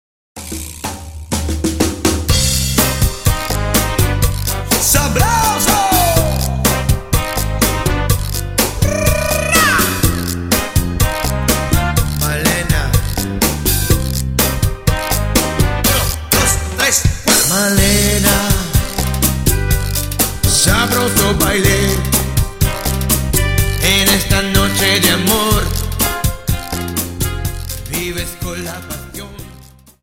Dance: Cha Cha 31